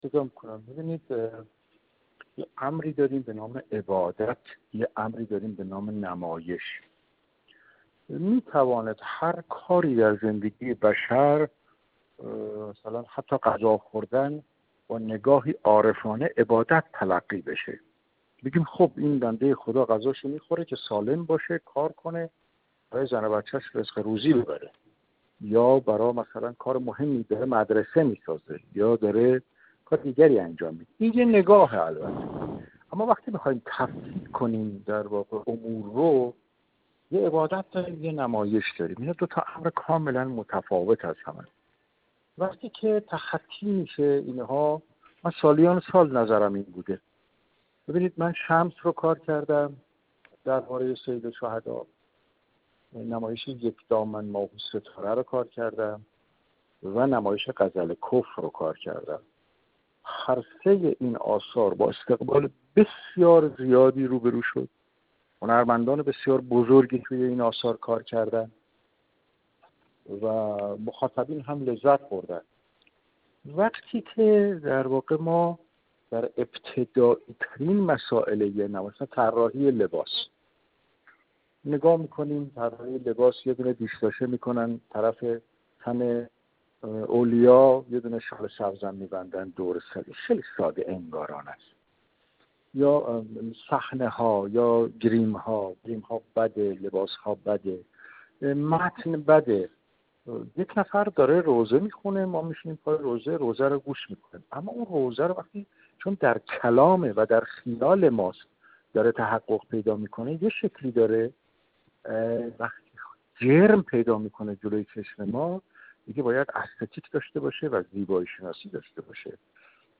با وی پیرامون جلوه‌های شور حسینی در سیما به گفت‌وگو نشسته‌ایم.